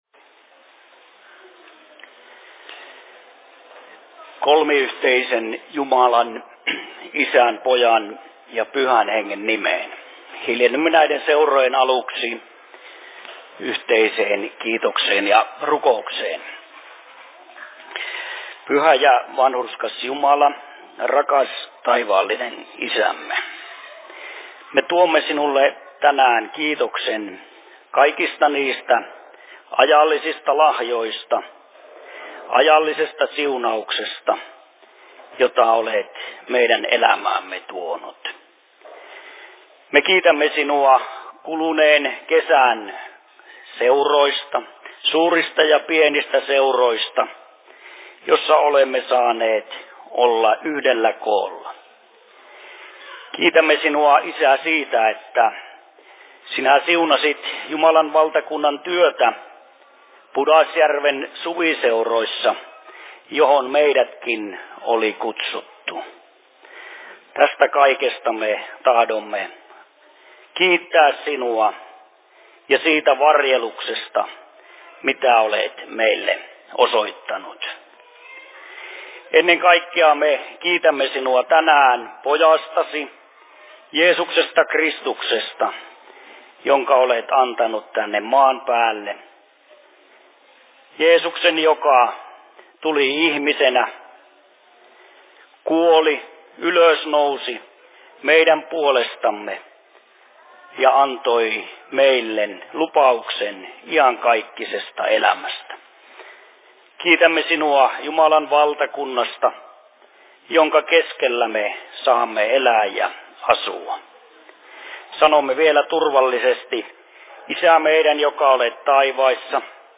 Seurapuhe Järvenpään RY:llä 15.09.2024 12.59
Paikka: Rauhanyhdistys Järvenpää